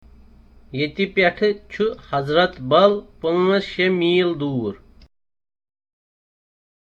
A conversation on visiting the mosque at Hazratbal, ten miles from downtown Srinagar.